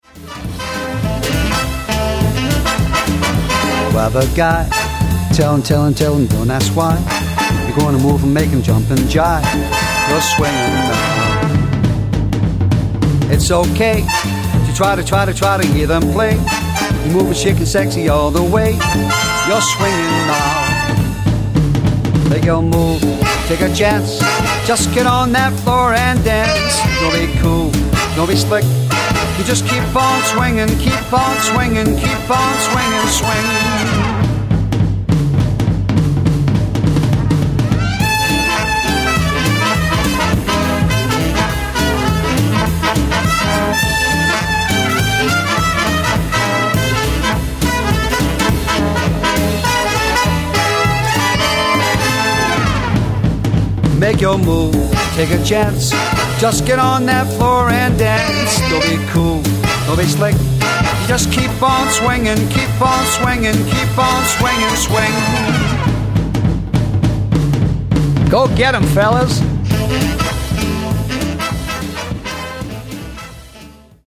swing
Tribute Music Samples